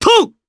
Clause-Vox_Jump_jp.wav